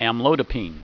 Pronunciation
(am LOE di peen)